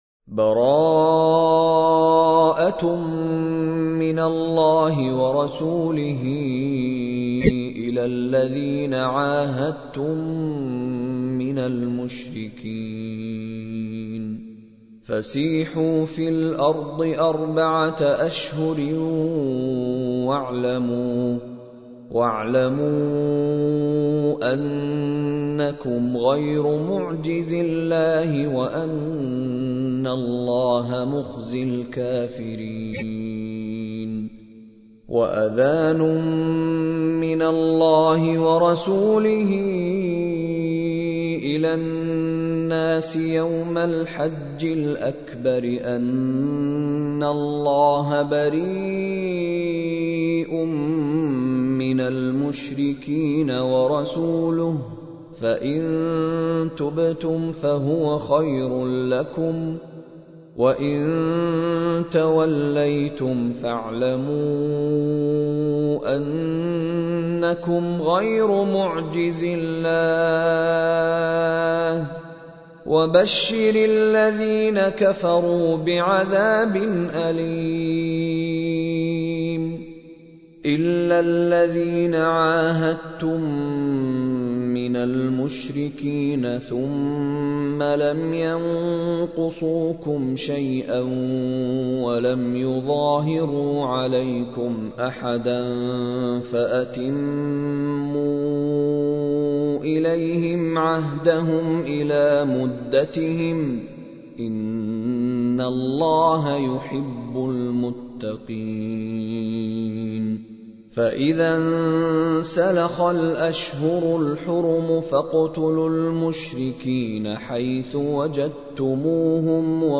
تحميل : 9. سورة التوبة / القارئ مشاري راشد العفاسي / القرآن الكريم / موقع يا حسين